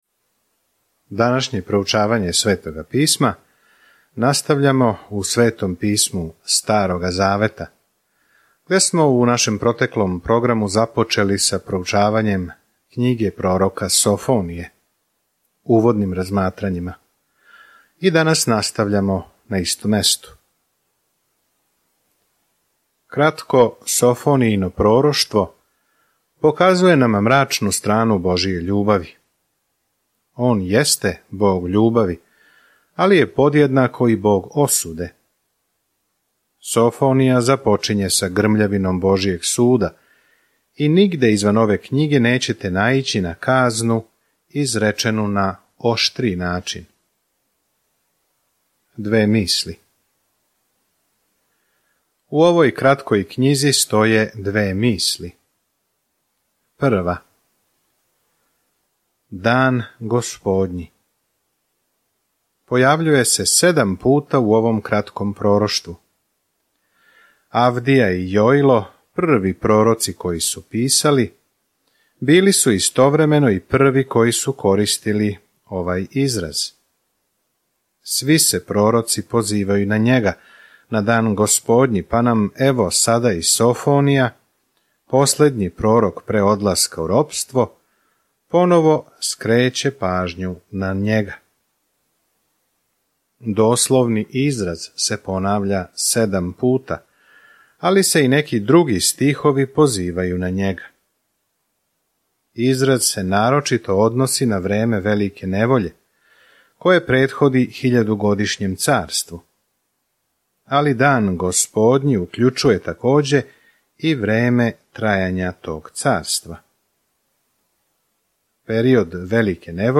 Sveto Pismo Knjiga proroka Sofonije 1:1-5 Dan 1 Započni ovaj plan Dan 3 O ovom planu Софонија упозорава Израел да ће им Бог судити, али им такође говори колико их воли и како ће им се једног дана радовати певајући. Свакодневно путујте кроз Софонију док слушате аудио студију и читате одабране стихове из Божје речи.